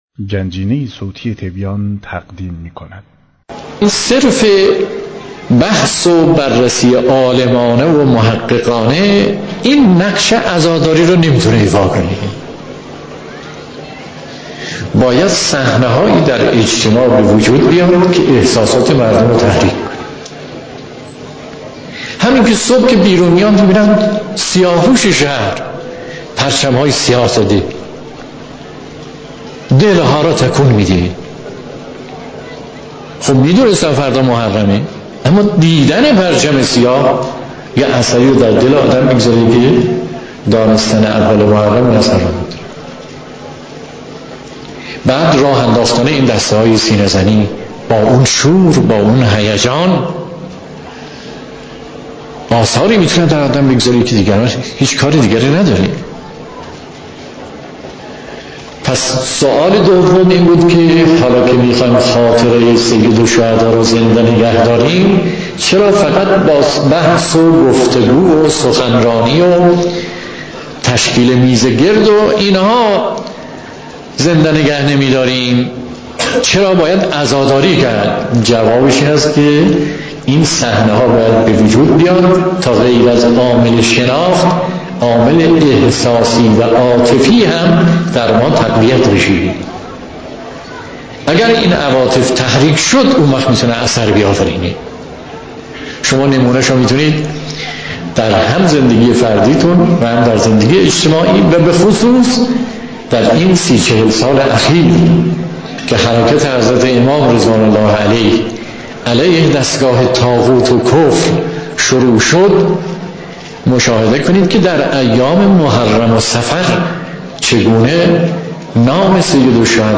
سخنرانی آیت‌الله مصباح یزدی با موضوع شبهاتی پیرامون عاشورا و بزرگداشت آن